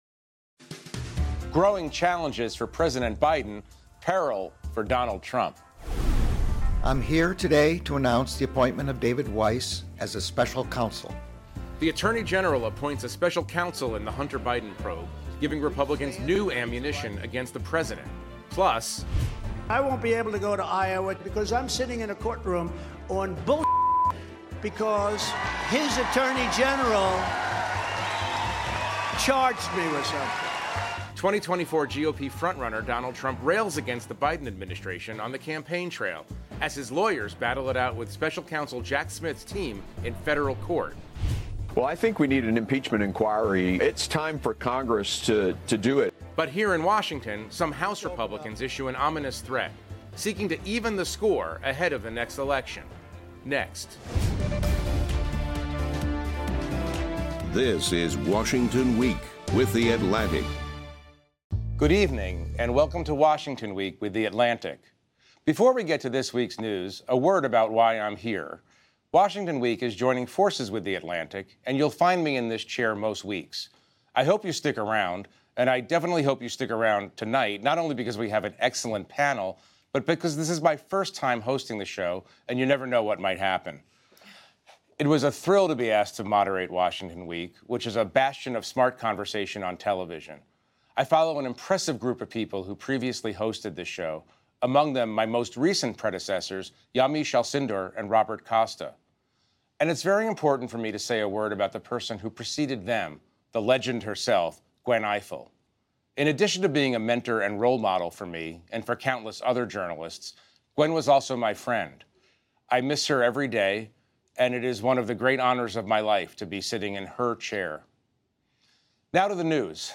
News, News Commentary, Politics